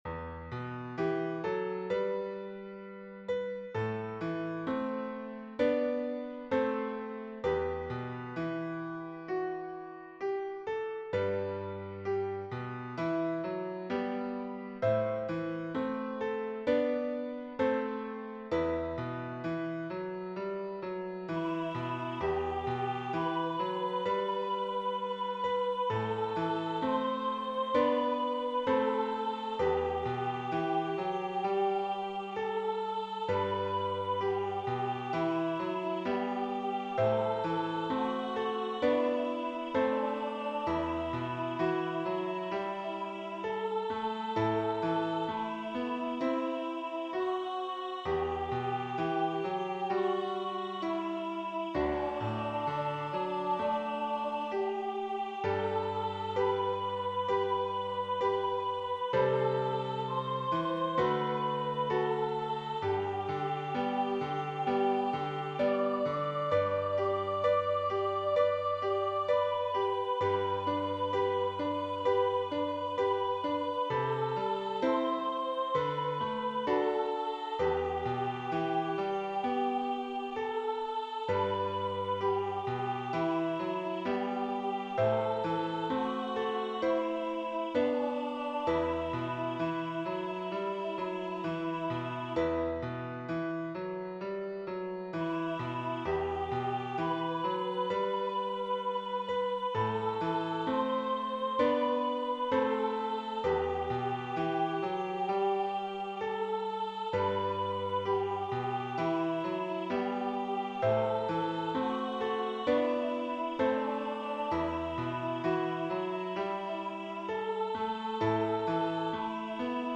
It is hauntingly beautiful to me.
I apologize for the exaggerated crescendos and decrescendos.
Medium Voice/Low Voice